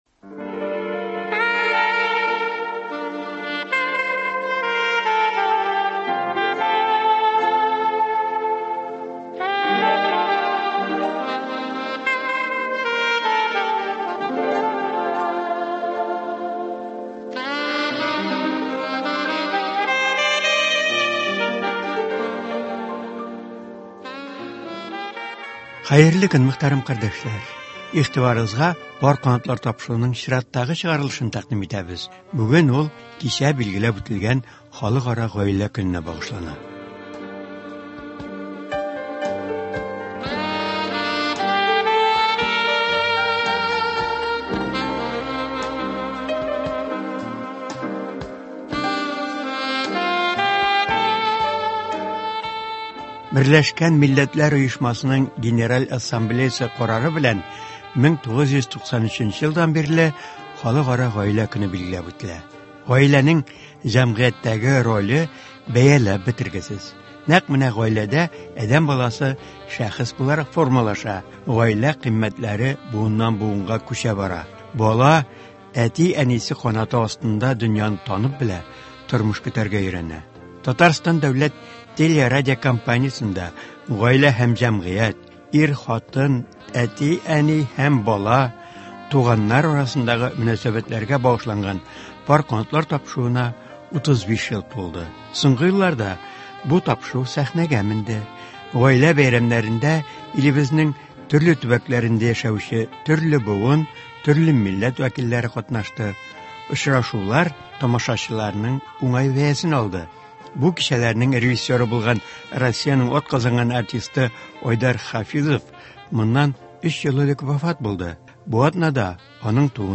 шул ук елны сәхнәдә үткәрелгән Гаилә бәйрәменең кыскартылган язмасы бирелә.